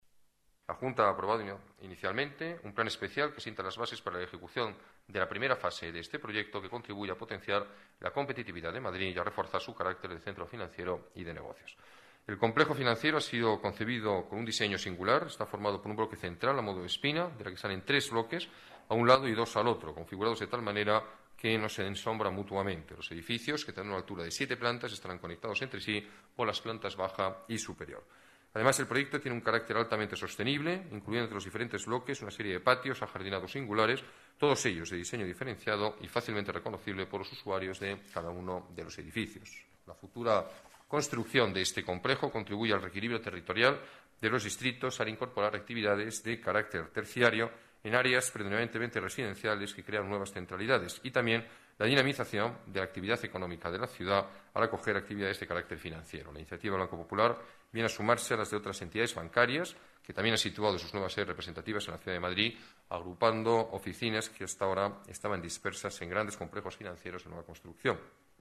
Nueva ventana:Declaraciones del alcalde de la Ciudad, Alberto Ruiz-Gallardón: Nuevo centro financiero